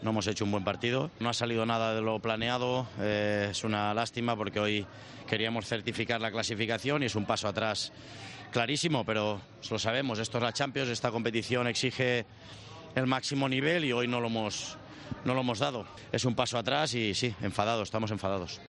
El entrenador del Barcelona analizó en Movistar la derrota ante el Shaktark y criticó el juego del equipo.